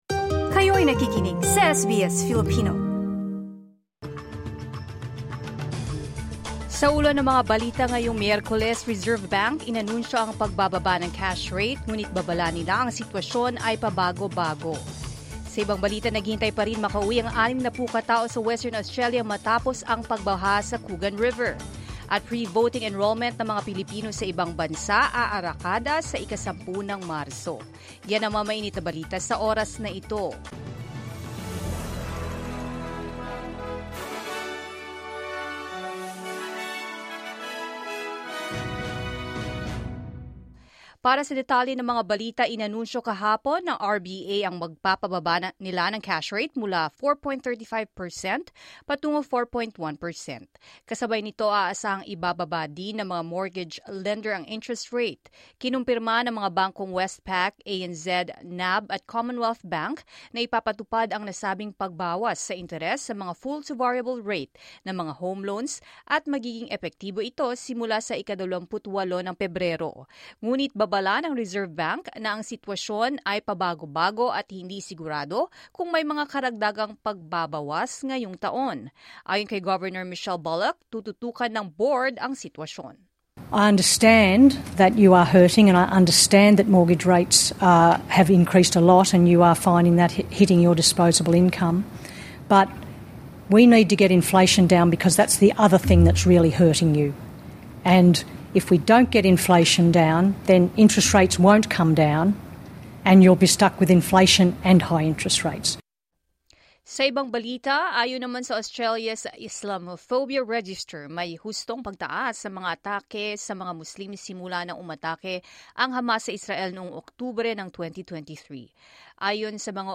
SBS News in Filipino, Wednesday 19 February 2025